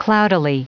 Prononciation du mot cloudily en anglais (fichier audio)
Vous êtes ici : Cours d'anglais > Outils | Audio/Vidéo > Lire un mot à haute voix > Lire le mot cloudily